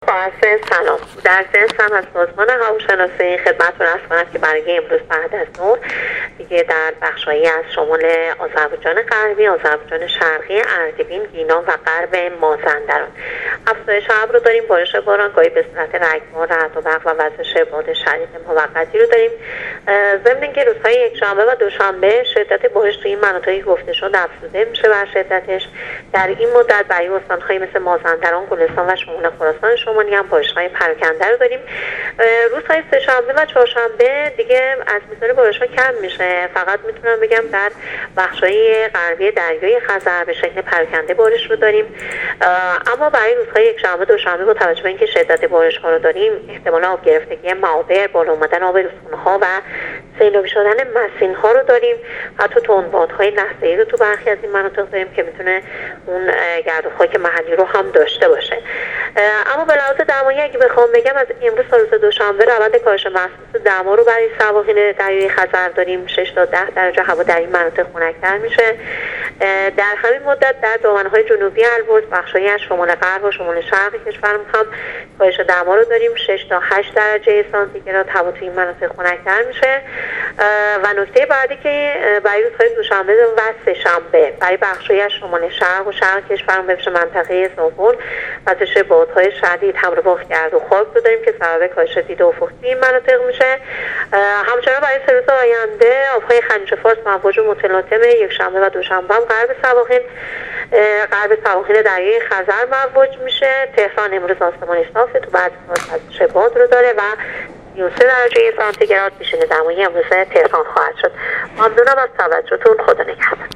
گزارش رادیو اینترنتی وزارت راه و شهرسازی از آخرین وضعیت آب‌وهوای ۱۶شهریور ۹۸/کاهش ۶ تا ۱۰ درجه‌ای دما در استان های شمالی/طوفان شن در زابل/آب‌های خلیج‌فارس مواج است